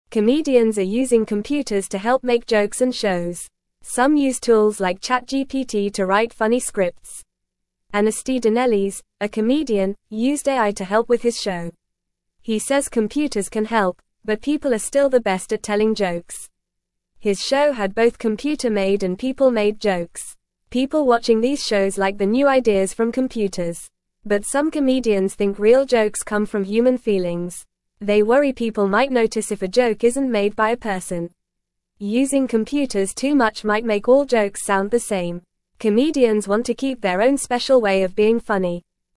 Fast
English-Newsroom-Beginner-FAST-Reading-Comedians-Use-Computers-to-Make-Jokes-and-Shows.mp3